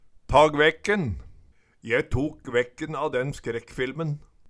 ta gvekken - Numedalsmål (en-US)
DIALEKTORD PÅ NORMERT NORSK ta gvekken ta skrekken Eksempel på bruk Je tok gvekken a den skrekkfilmen Tilleggsopplysningar Kjelde